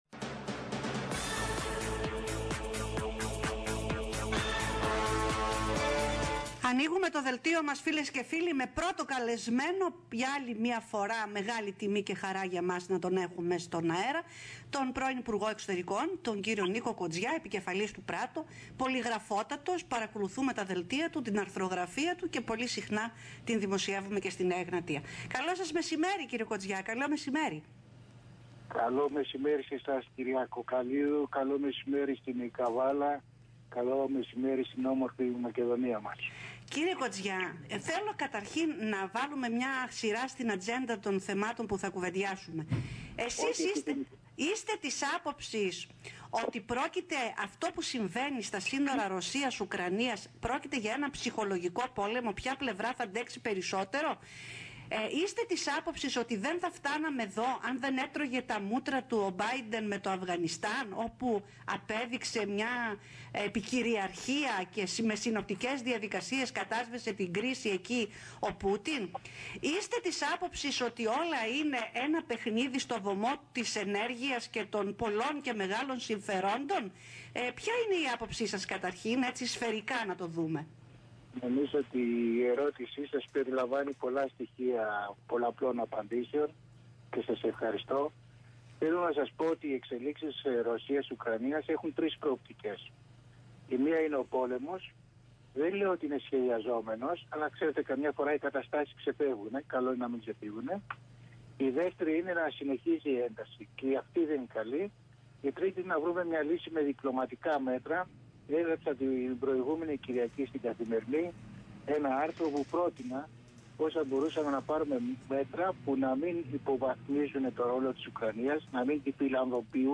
kotzias-synenteyxi-alpha-radio.mp3